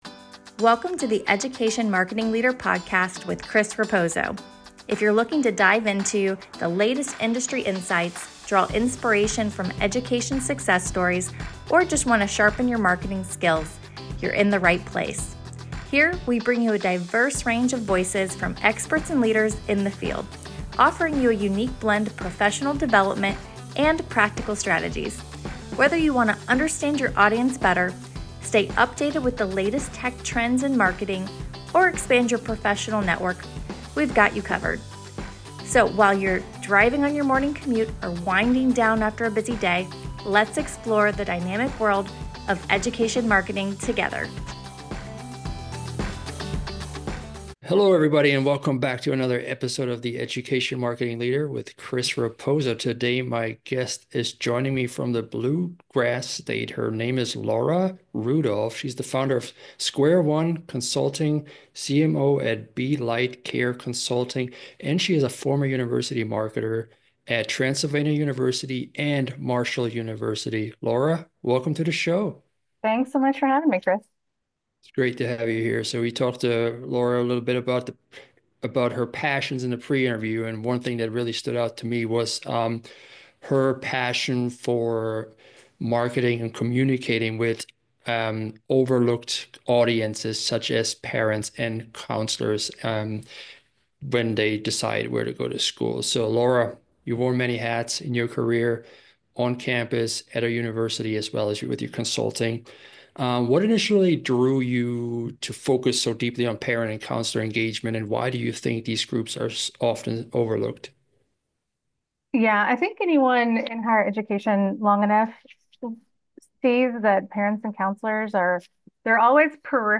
Marketing the Humanities - Strategies for Elevating SHAPE Subjects in Higher Education a Conversation